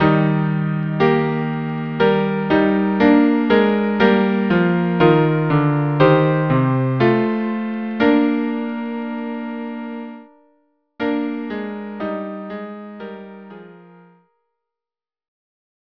Instrumentation: Violin 1; Violin 2 or Viola; Cello